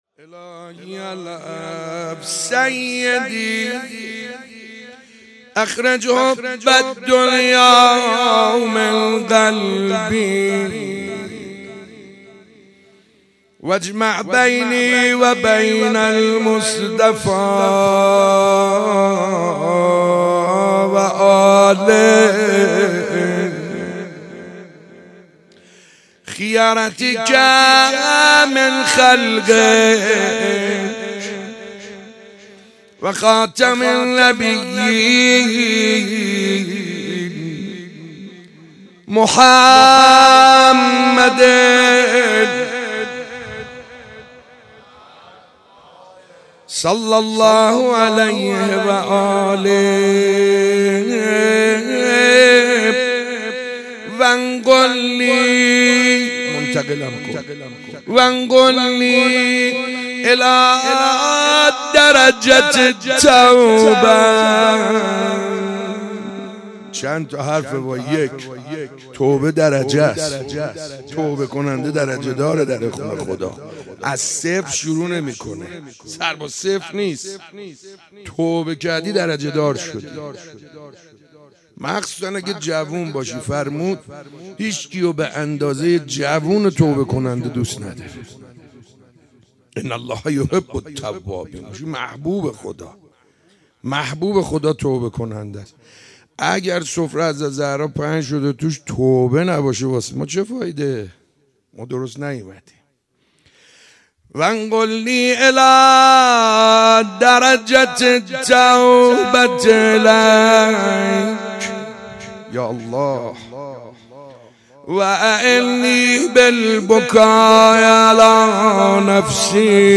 شب 4 فاطمیه
روضه - من مرغ عشق حیدرم